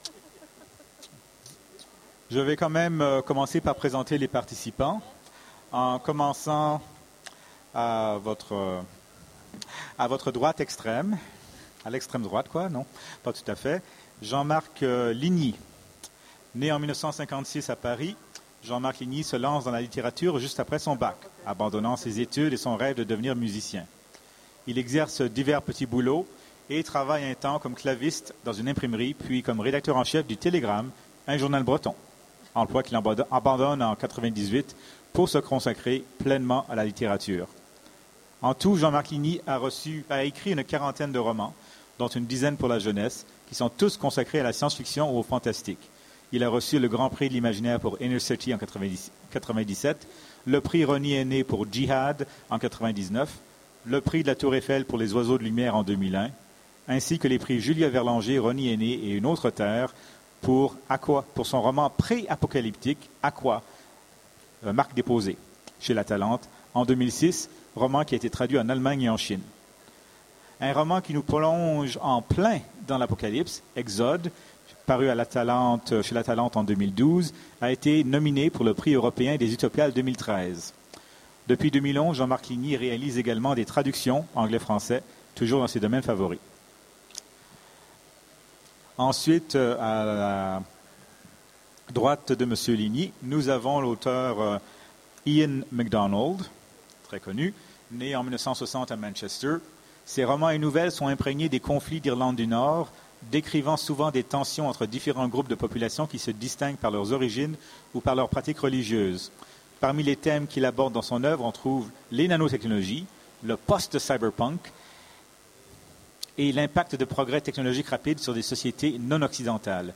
Utopiales 13 : Conférence Quand la Nature contre-attaque !